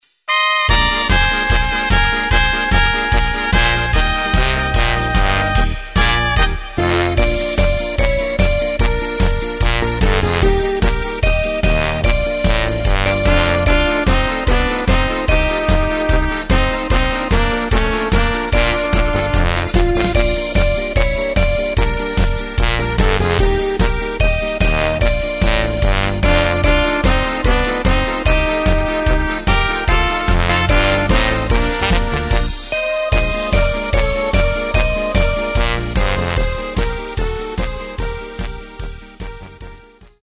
Takt:          2/4
Tempo:         148.00
Tonart:            Eb
Polka aus dem Jahr 2025!